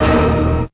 55bf-orc12-d#3.wav